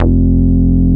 P MOOG F2F.wav